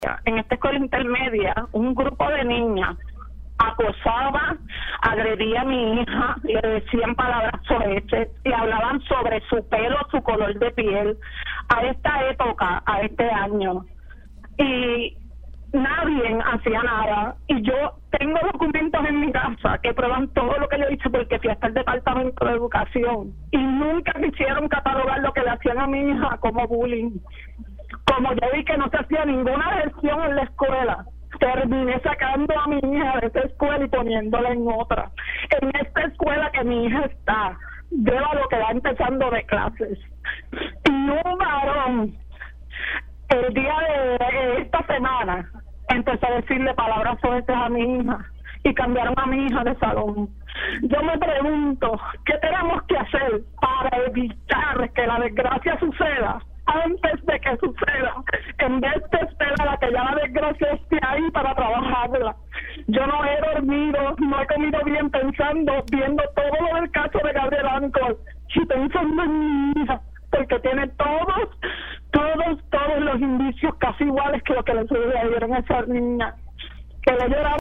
Una madre, que decidió hablar en condición de anonimato en Pega’os en la Mañana, afirmó que en ambas escuelas de nivel intermedio en las que ha estado su hija, de 12 años, ha enfrentado acoso por parte de sus compañeros y compañeras, sin que ninguna autoridad tome acción.